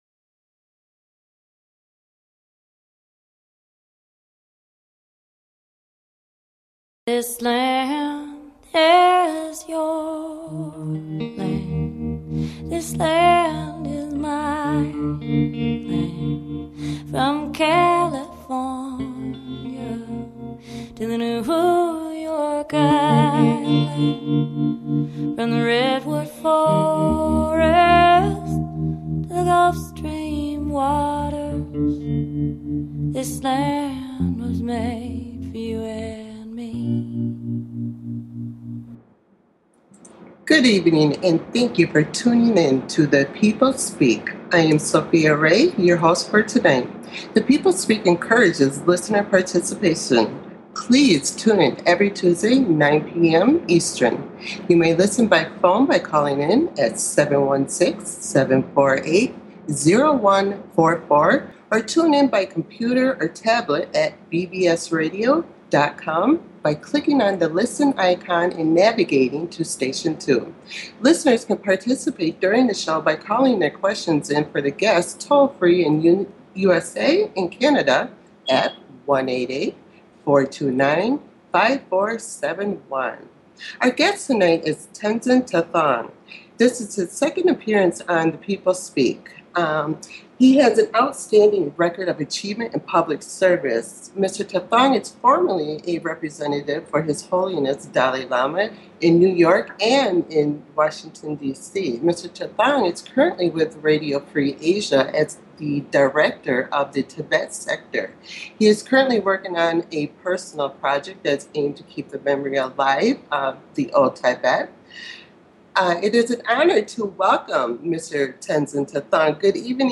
Talk Show Episode, Audio Podcast, TIBETAN DIRECTOR - RADIO FREE ASIA and Tenzin Tethon Speak About Tibet on , show guests , about Tenzin Tethon,Radio Free Asia,Tibetan Director,His Holiness Dalai Lama,Tibet, categorized as Education,History,News,Politics & Government,Religion,Spiritual
Guest, Tenzin Tethon
TENZIN TETHON - former Tibetan Prime Minister not Tibetan Director of Radio Free Asia discusses the history and enduring plight of the Tibetan people and answer audience questions from around the world.